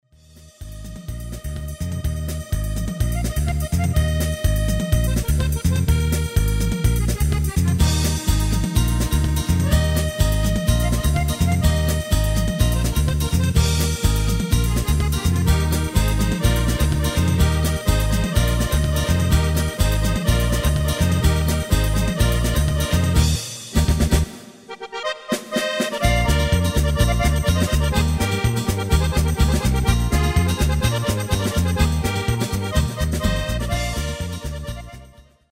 Basic MIDI File Euro 8.50